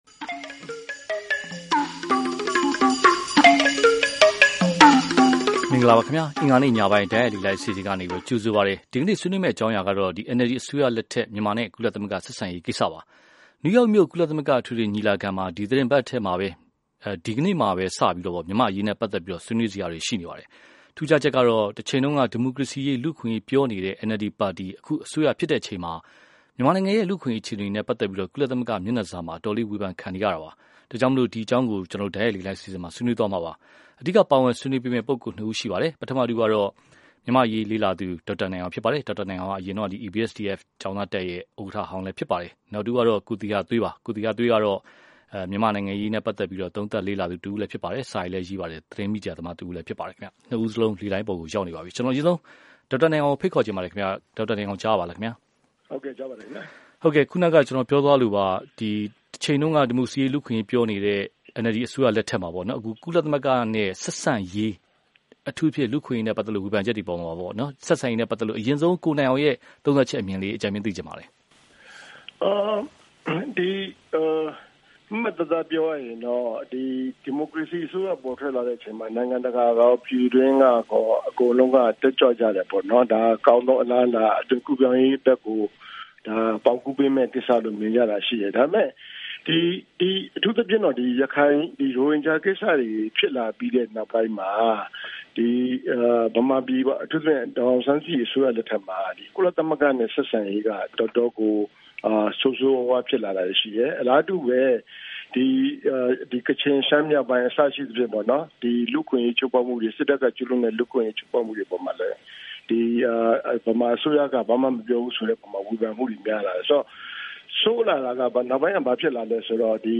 NLD အစိုးရလက်ထက် မြန်မာနဲ့ ကုလ ဆက်ဆံရေး (တိုက်ရိုက်လေလှိုင်းဆွေးနွေးပွဲ)